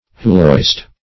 huloist - definition of huloist - synonyms, pronunciation, spelling from Free Dictionary Search Result for " huloist" : The Collaborative International Dictionary of English v.0.48: Huloist \Hu"lo*ist\, n. See Hyloist .